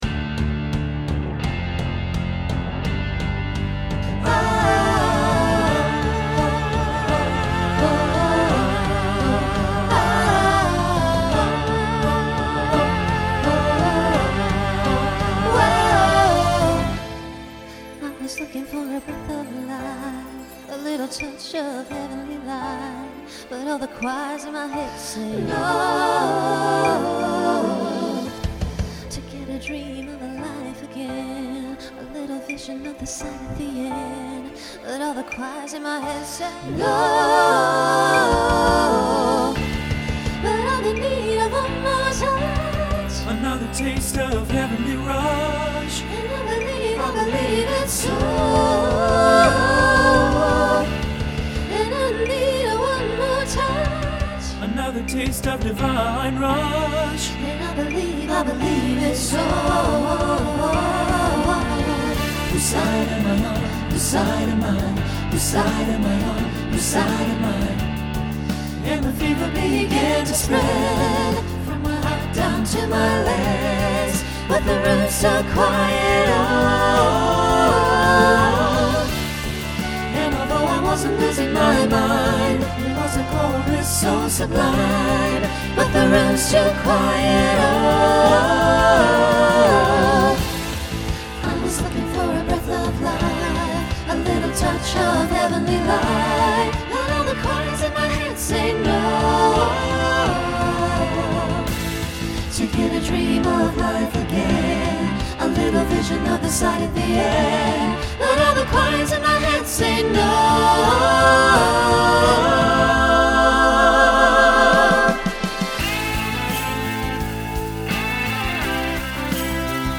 Voicing SATB Instrumental combo Genre Folk , Rock
Mid-tempo